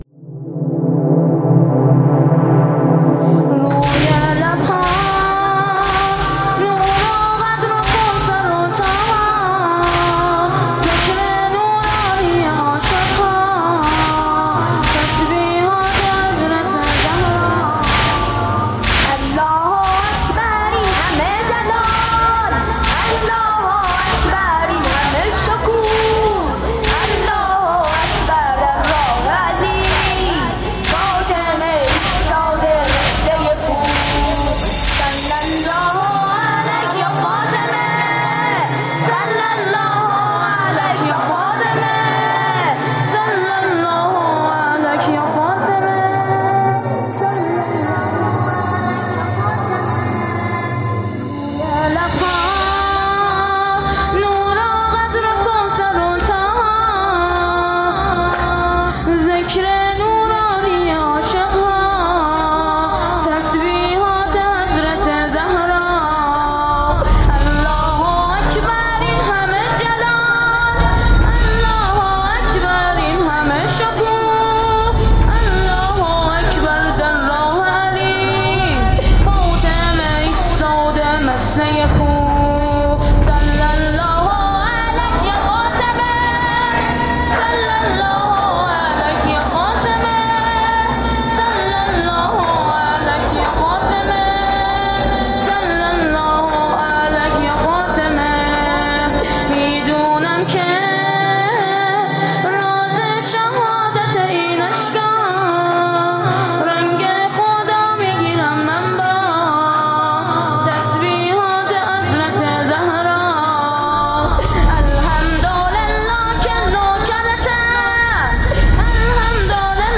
استودیویی